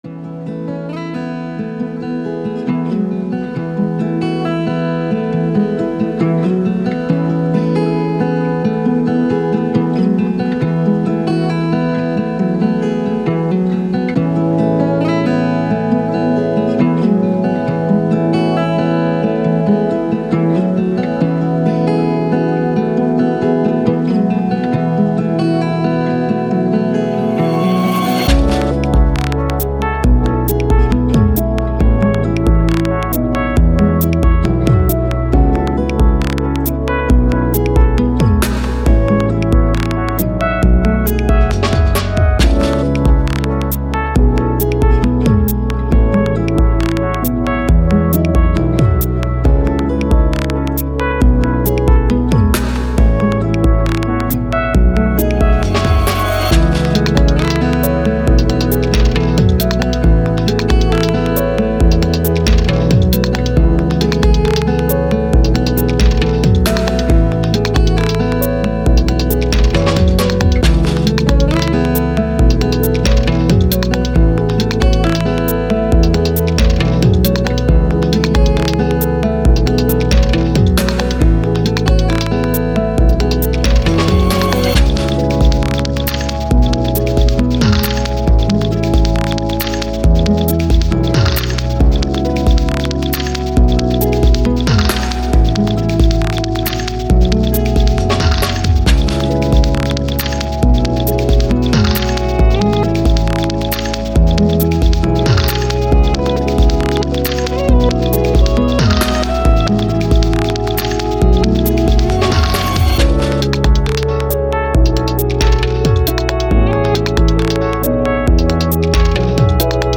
Downtempo, Electronic, IDM, Thoughtful, Journey